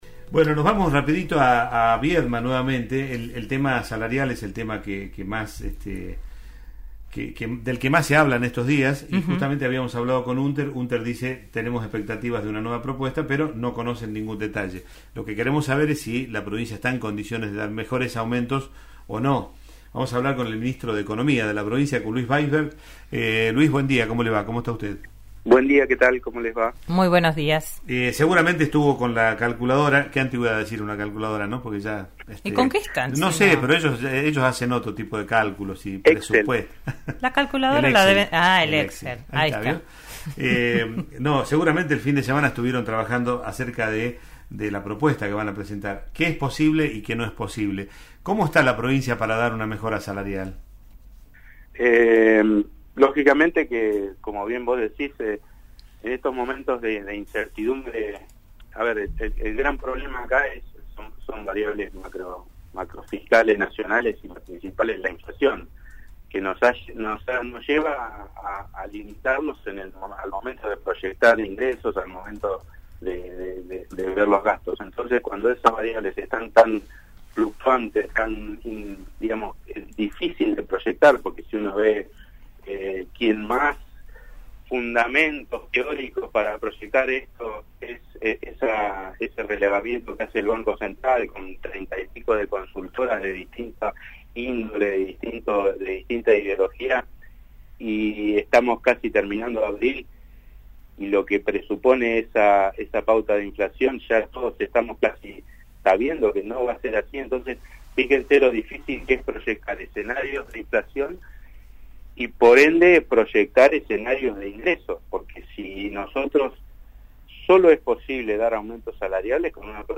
El ministro de Economía, Luis Vaisberg, defendió en RN RADIO la transparencia de la compra por encima de los valores de mercado.